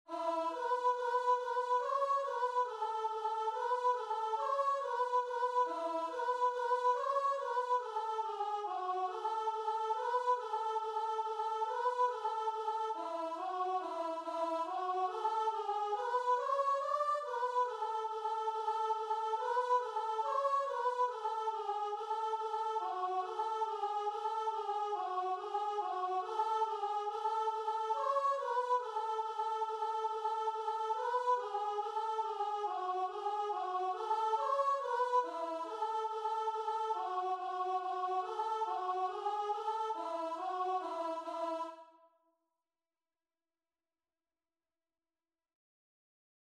Free Sheet music for Voice
Traditional Music of unknown author.
4/4 (View more 4/4 Music)
A major (Sounding Pitch) (View more A major Music for Voice )
Voice  (View more Easy Voice Music)
Christian (View more Christian Voice Music)